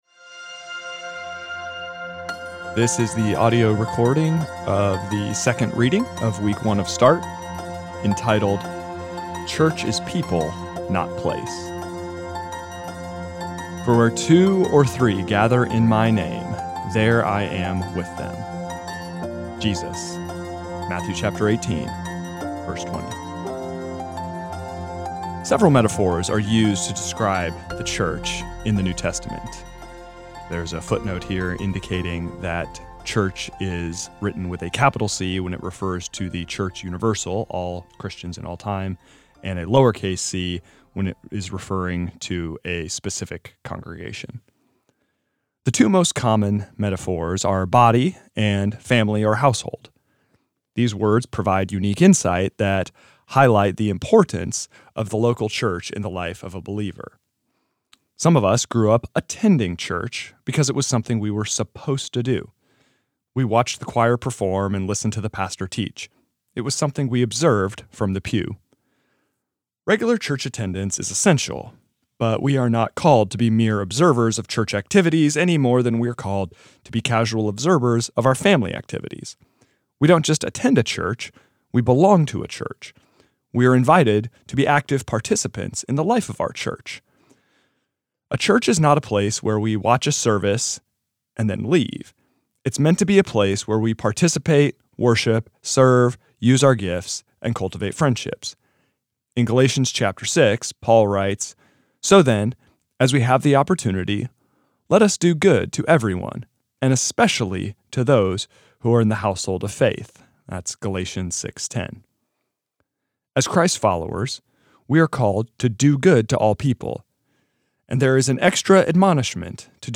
This is the audio recording of the second reading of week two of Start, entitled Church is People, Not Place.